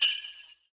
BJINGLEBEL2J.wav